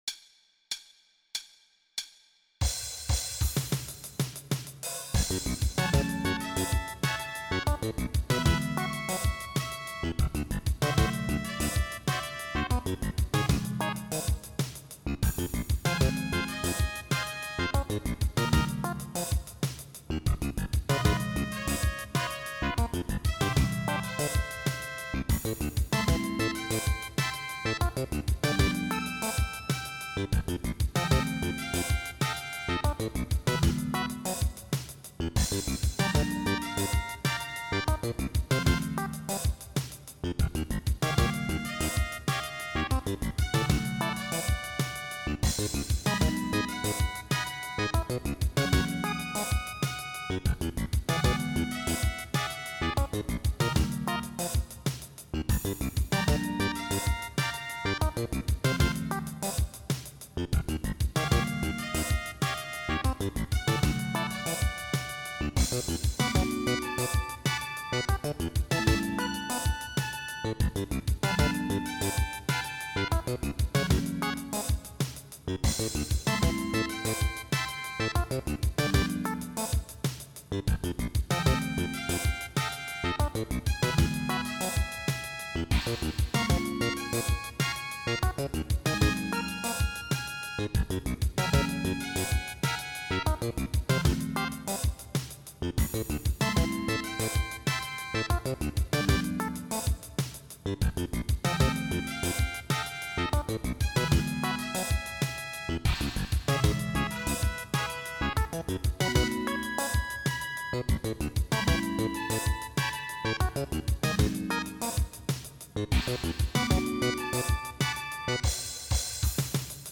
16beat